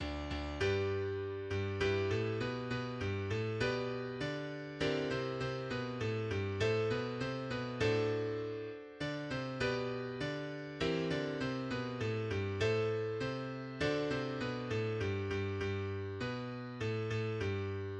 Das Bürgerlied (auch Ob wir rote, gelbe Kragen oder Königsberger Volkslied) ist ein 1845 von Adalbert Harnisch (1815–1889) geschriebenes Volkslied. Ursprünglich für den Bürgerverein der ostpreußischen Stadt Elbing geschrieben, entwickelte es sich rasch zu einem beliebten Lied zunächst in der nationalliberalen und später in der Arbeiterbewegung.